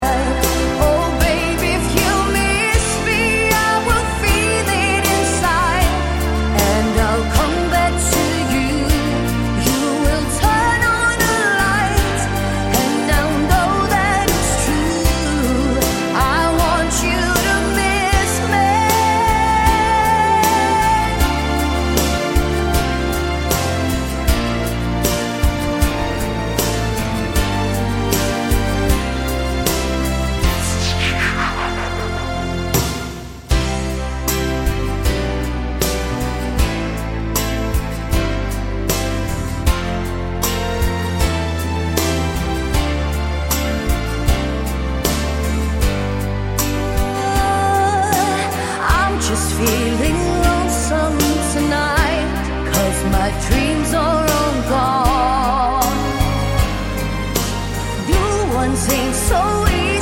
With Lead Female Duets 4:04 Buy £1.50